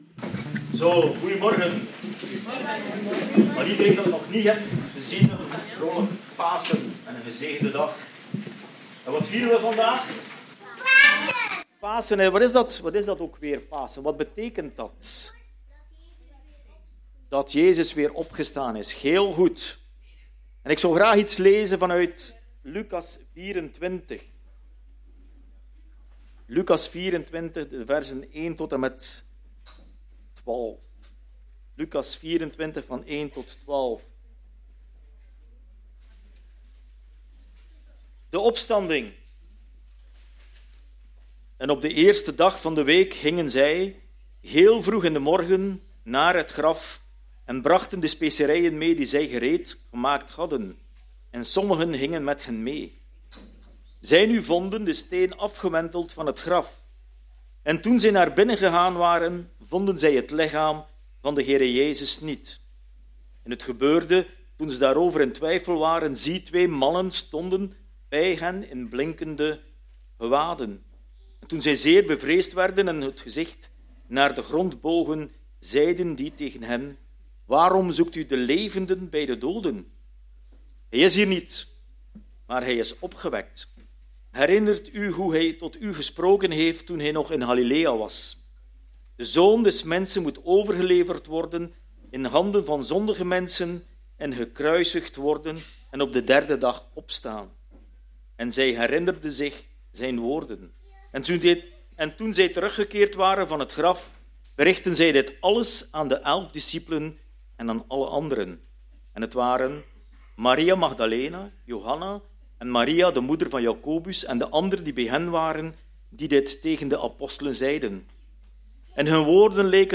Lucas 23—24 en Johannes 19—21 Dienstsoort: Paasdienst Wat als de dood niet het laatste woord heeft?